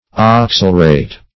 oxalurate - definition of oxalurate - synonyms, pronunciation, spelling from Free Dictionary Search Result for " oxalurate" : The Collaborative International Dictionary of English v.0.48: Oxalurate \Ox`a*lur"ate\, n. (Chem.) A salt of oxaluric acid.